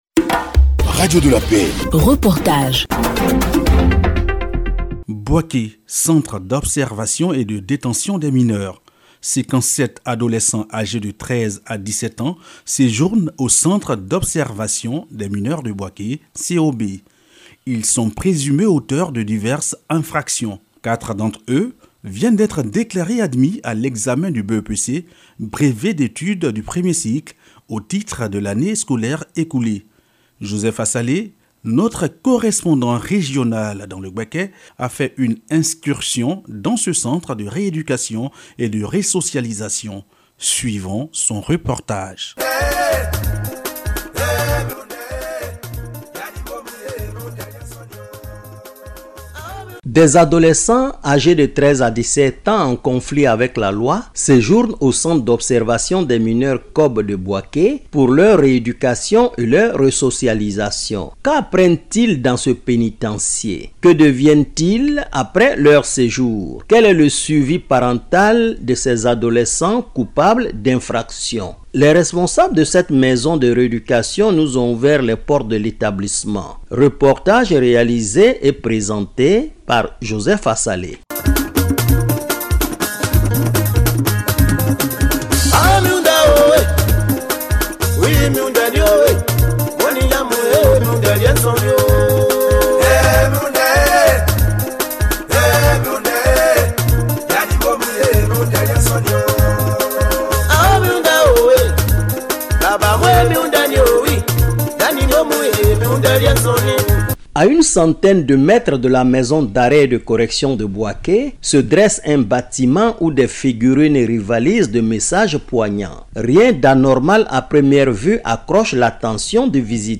Reportage – Le Centre d’Observation des Mineurs de Bouaké (COB) - Site Officiel de Radio de la Paix